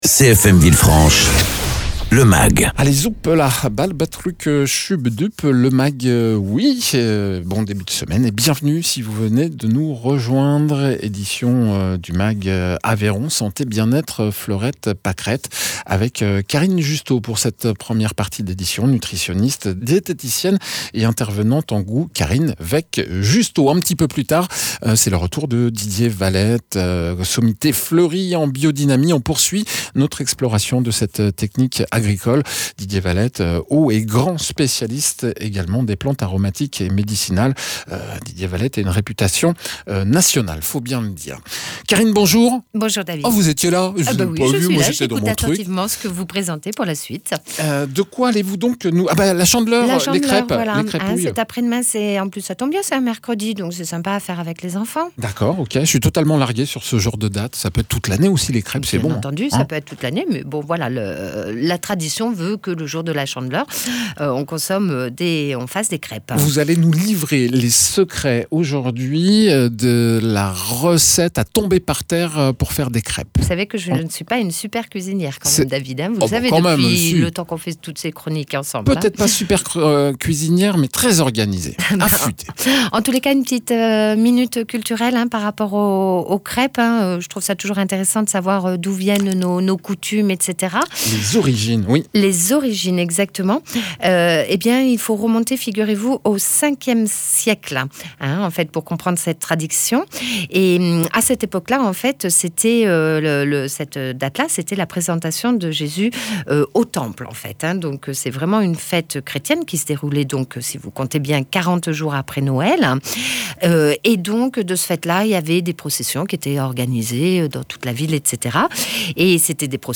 nutritionniste diététicien